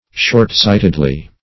shortsightedly - definition of shortsightedly - synonyms, pronunciation, spelling from Free Dictionary
[1913 Webster] -- Short"sight`ed*ly, adv.
shortsightedly.mp3